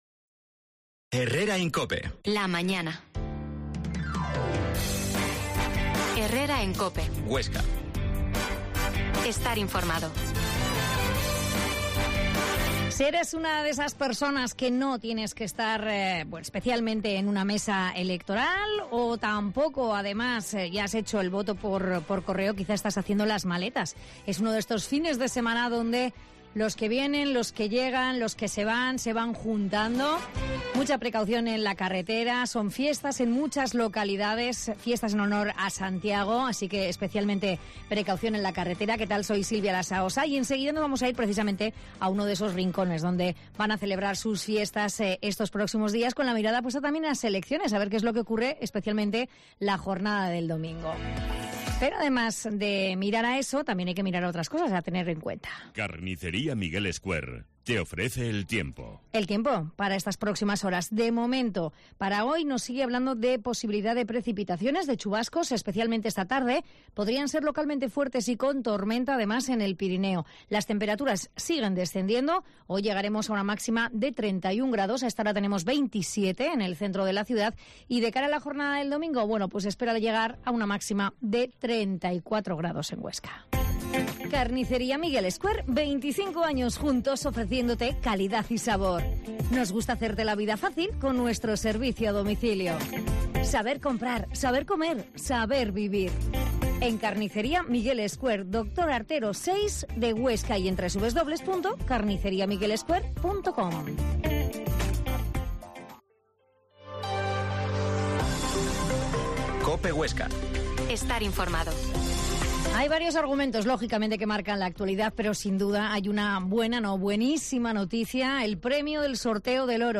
Herrera en COPE Huesca 12.50h Entrevista al alcalde de Grañén, Carlos Samperiz